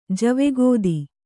♪ jave gōdi